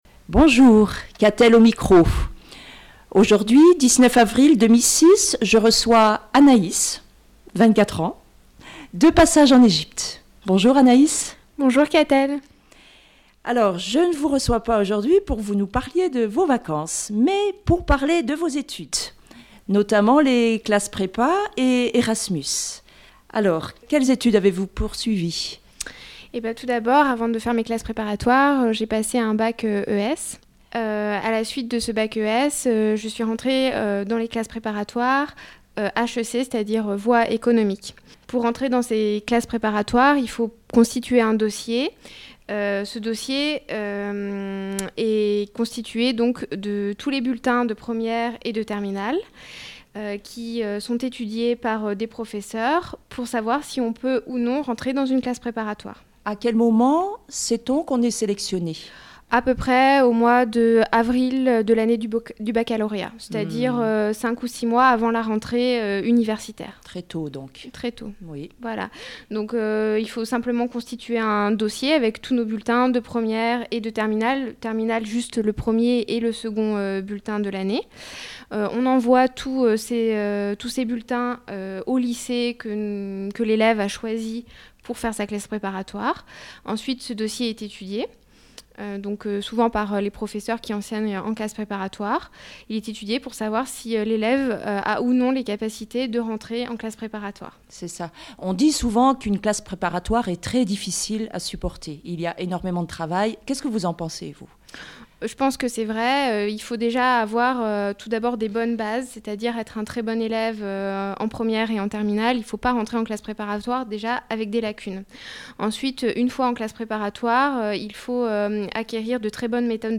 Classes prépa, Erasmus, Sup de Co : témoignage d’une étudiante